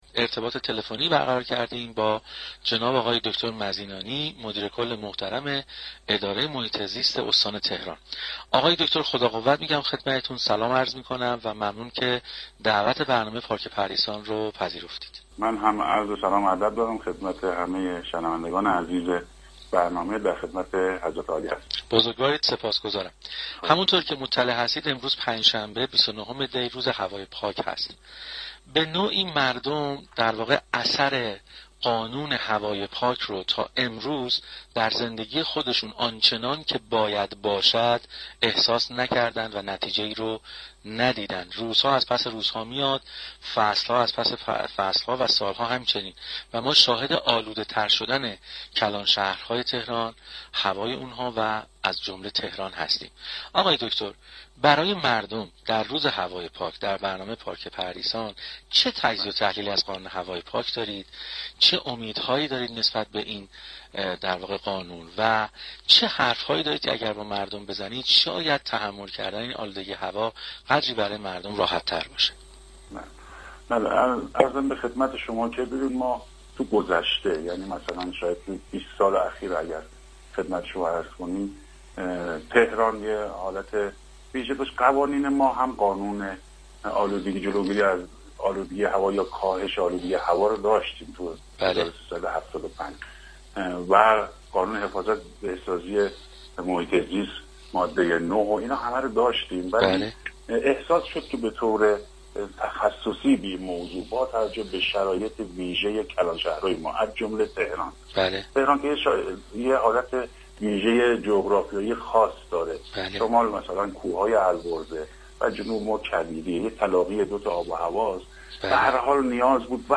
به گزارش پایگاه اطلاع رسانی رادیو تهران؛ علیرضا مزینانی مدیركل حفاظت محیط زیست استان تهران در گفت و گو با «پارك پردیسان» درخصوص آلودگی هوای پایتخت اینگونه اظهار داشت: تهران یك حالت ویژه جغرافیایی دارد، از شمال با كوه‌های البرز و در جنوب با كویر احاطه شده است.